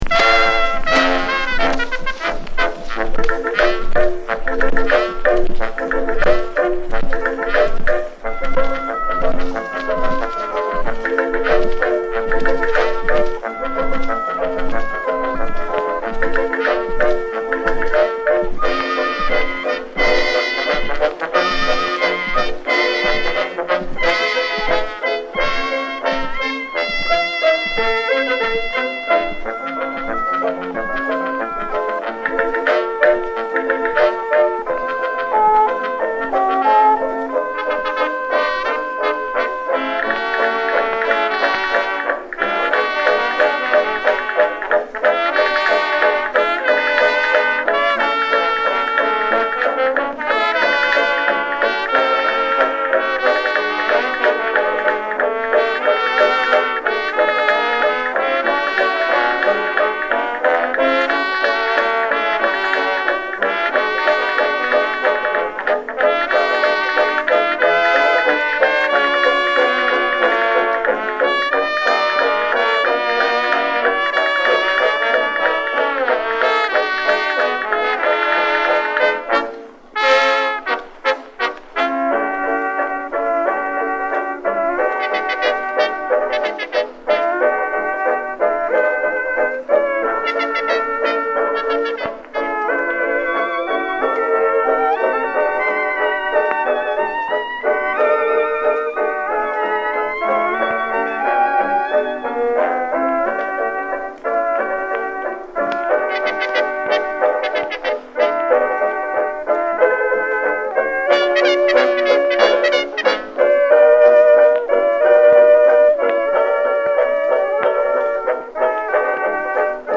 Here are some more Edison Diamond Discs I’ve acquired.